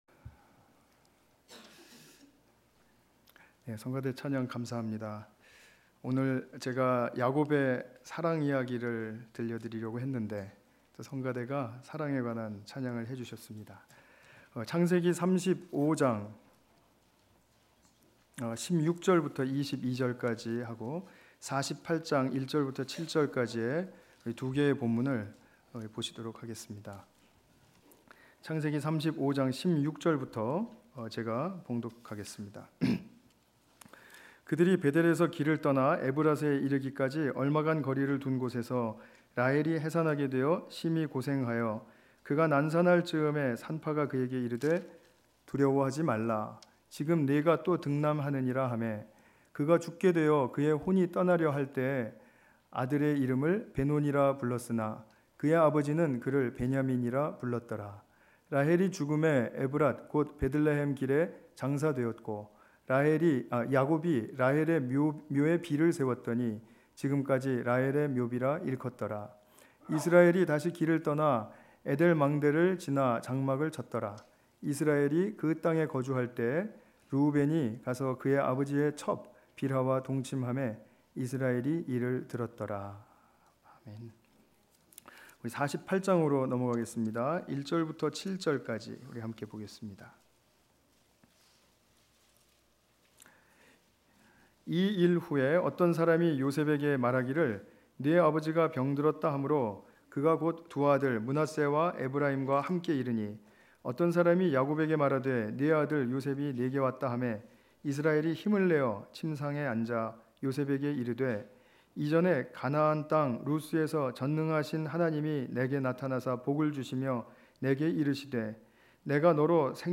48장 1 ~ 7절 관련 Tagged with 주일예배